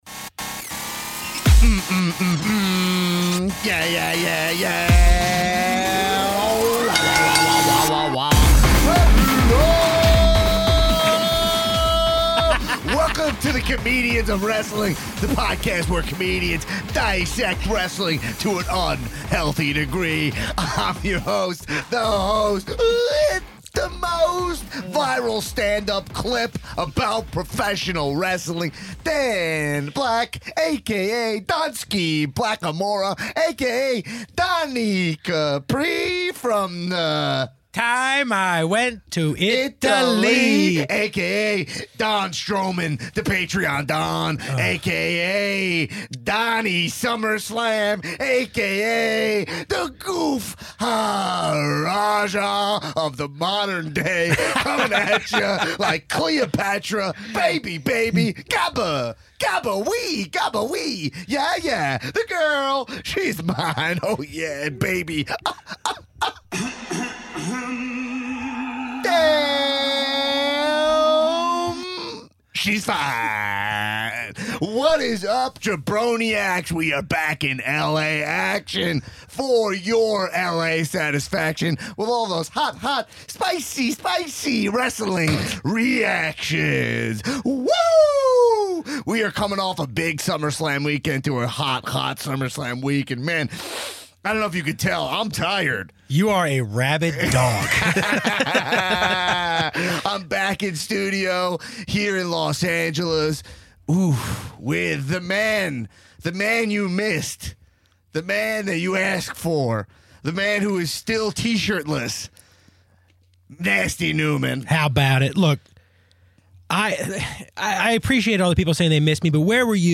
in the LA studios